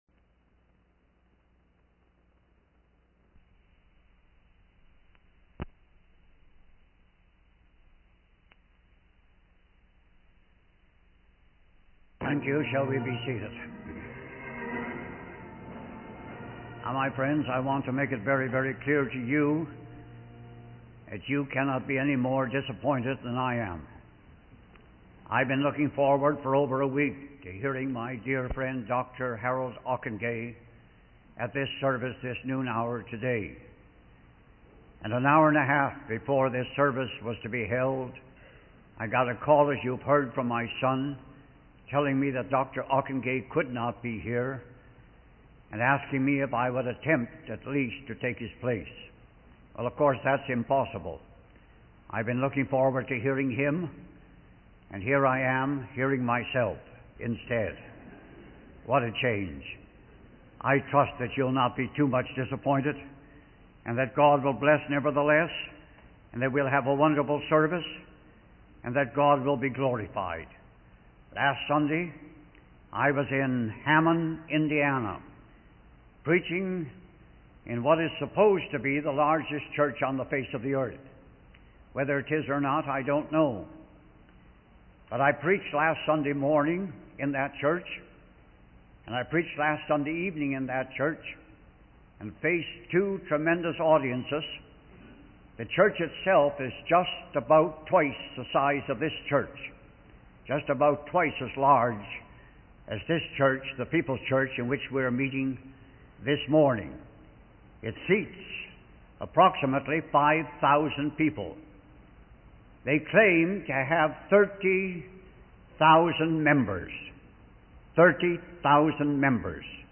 In this sermon, the speaker emphasizes the importance of publishing the gospel among all nations before the end of this age. They believe that once every nation has had the opportunity to hear the message of salvation, Jesus Christ will take over the reins of government and rule the world for a thousand years. The speaker passionately appeals for the tools needed to finish the job of evangelizing the world, drawing inspiration from Sir Winston Churchill's plea for tools to win the war.